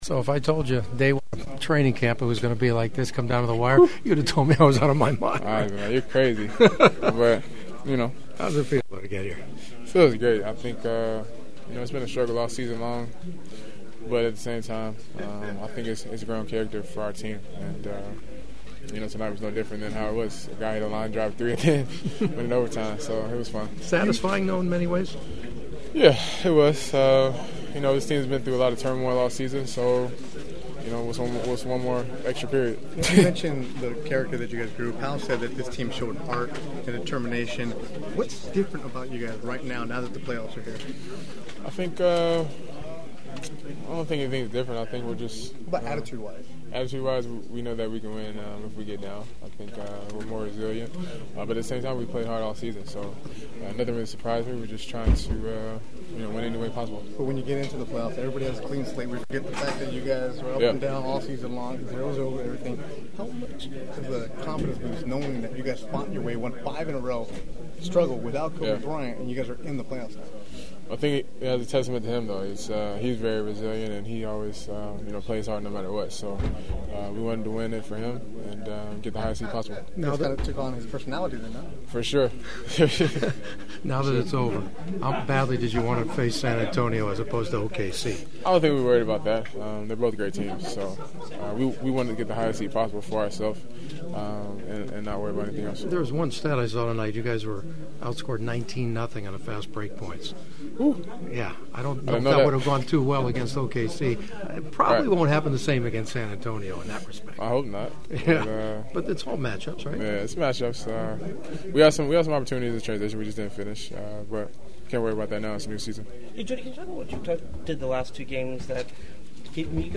I had some great postgame locker room chats that unfortunately I won’t be able to share with you since they were messed up by a microphone malfunction.